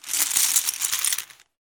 pokerchips.ogg